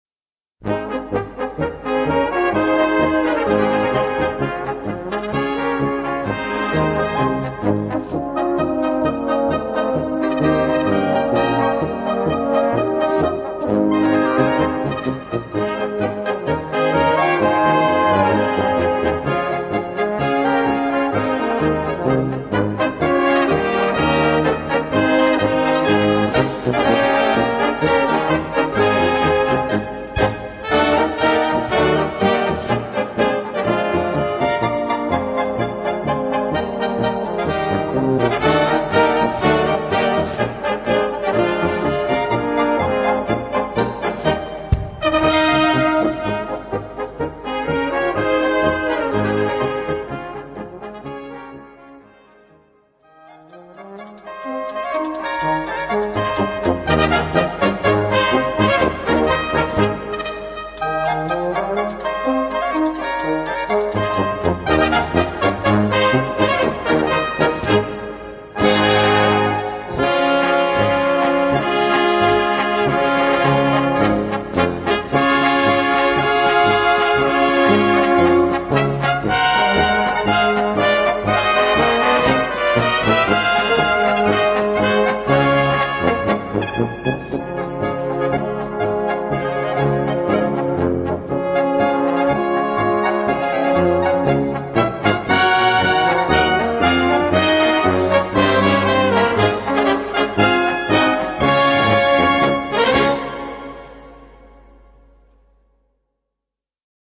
Gattung: Polka
Besetzung: Blasorchester
Eine echte böhmische Polka.